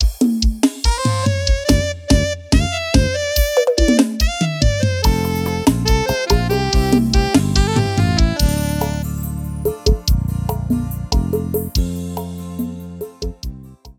• Demonstrativo Arrocha: